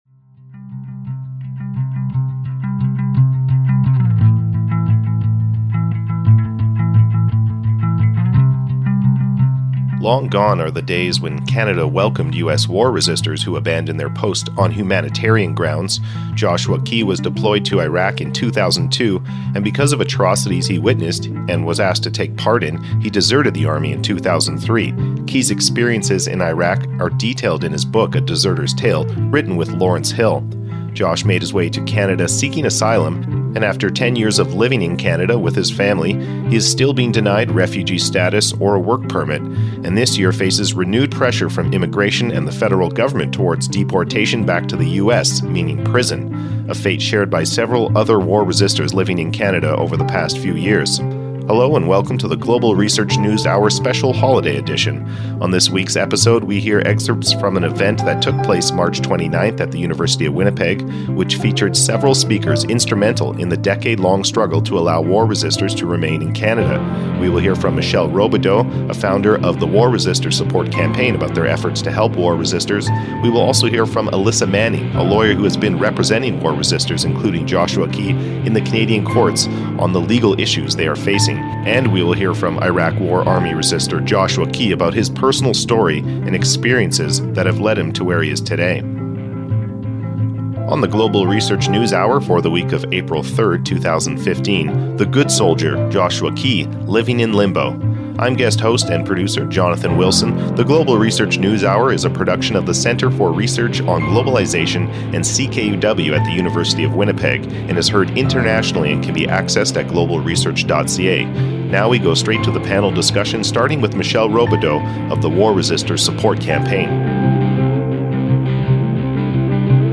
Speeches by a War resister, his lawyer and a War Resisters Campaigner